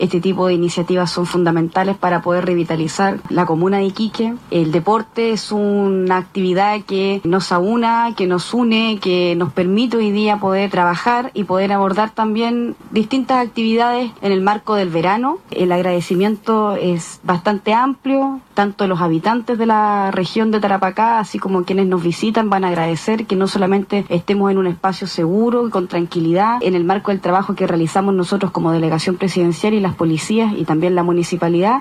La delegada presidencial de Tarapacá, Ivonne Donoso, destacó la iniciativa deportiva regional, afirmando que mejora la calidad de vida y revitaliza la ciudad.